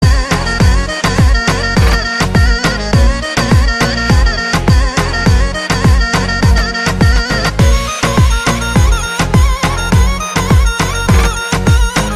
ingilizce-halay-audiotrimmer.mp3